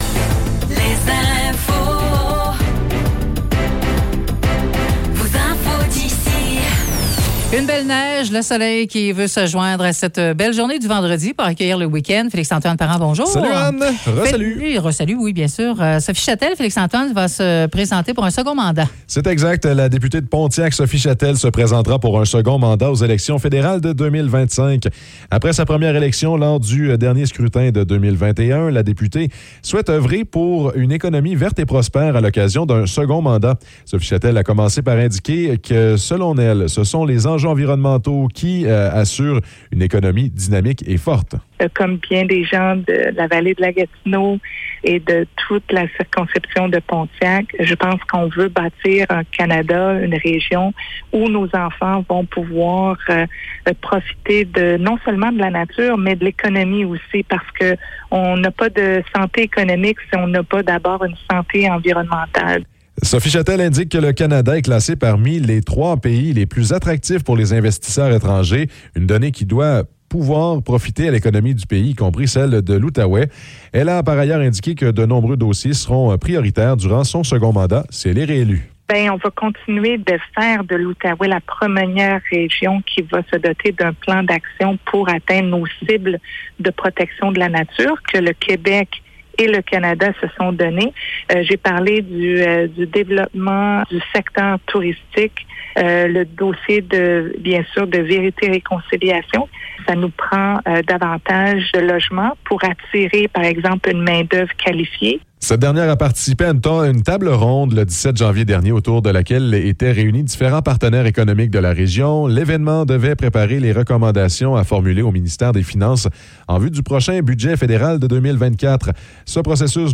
Nouvelles locales - 16 février 2024 - 10 h